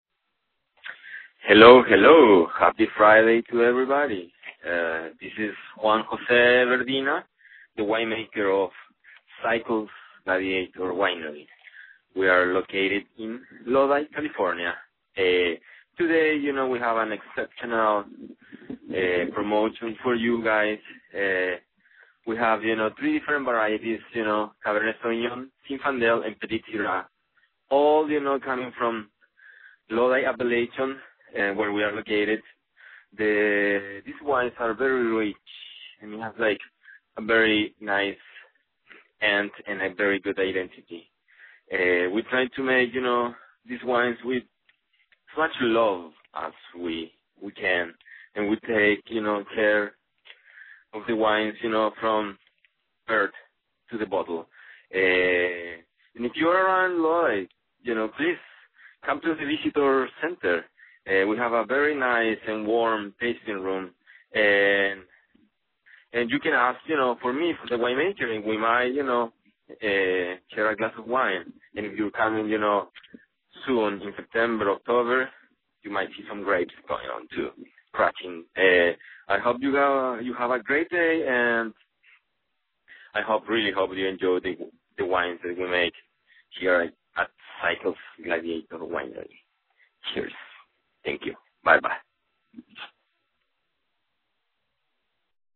Vintner Voicemail